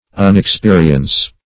Unexperience \Un`ex*pe"ri*ence\